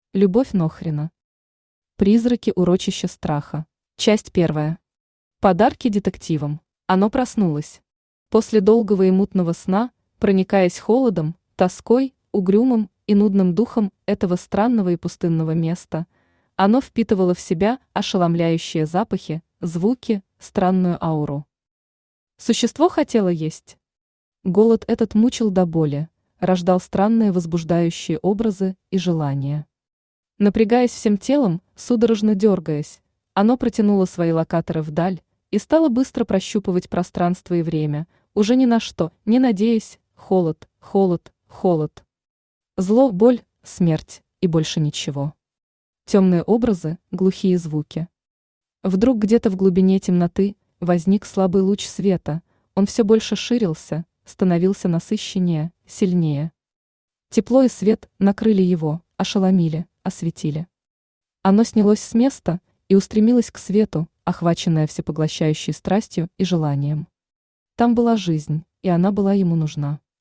Аудиокнига Призраки урочища страха | Библиотека аудиокниг
Aудиокнига Призраки урочища страха Автор Любовь Федоровна Нохрина Читает аудиокнигу Авточтец ЛитРес.